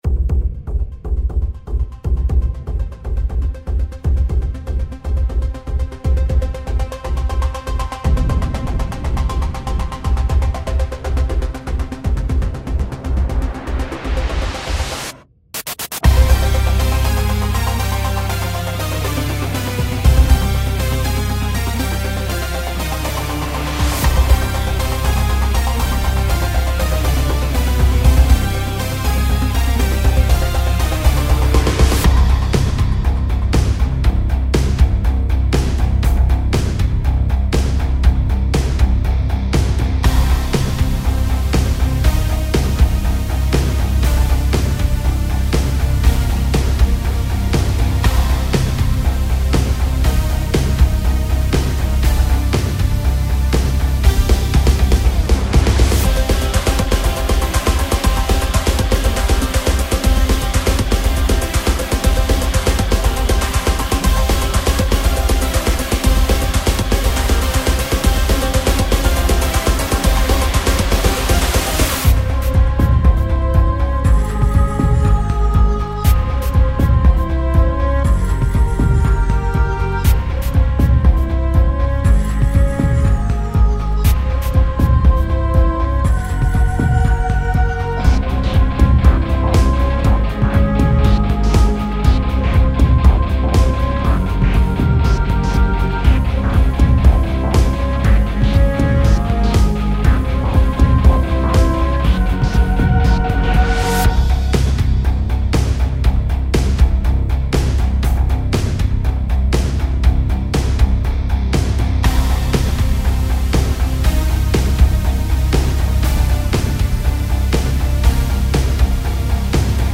轟音のギターリフと重厚なビートが、陰鬱なメロディと共に、心の奥底に隠された恐怖や怒りを解放させるでしょう。